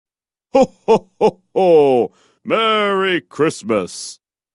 ho ho ho merry christmas sound effect - Download Free MP3
ho-ho-ho-merry-christmas-sound-effect-made-with-Voicemod.mp3